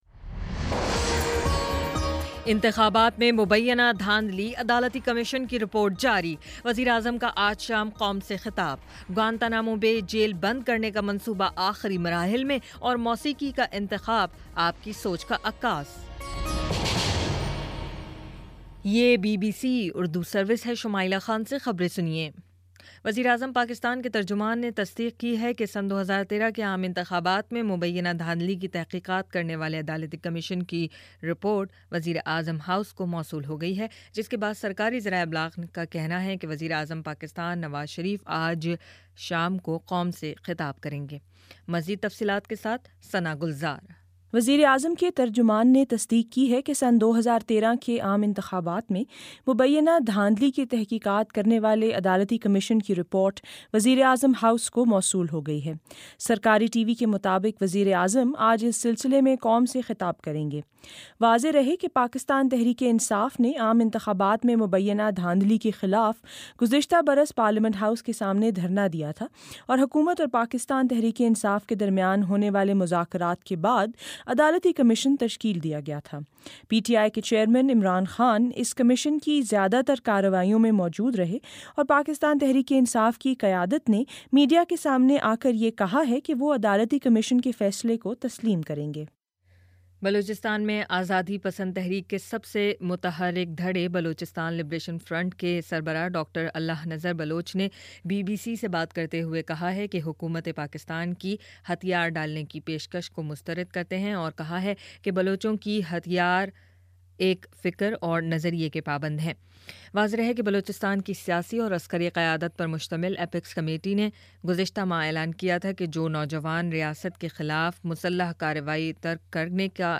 جولائی 23: شام پانچ بجے کا نیوز بُلیٹن